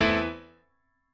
admin-leaf-alice-in-misanthrope/piano34_9_008.ogg at main